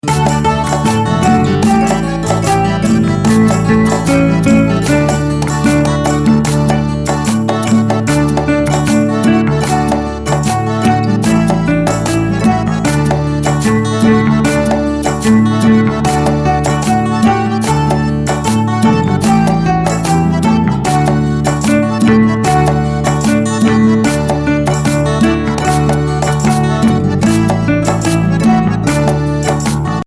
mp3 Instrumental Song Track